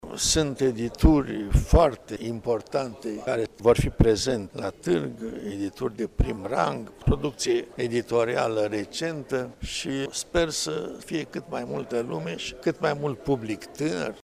într-o conferinţă de presă defăşurată la sediul studioului nostru de radio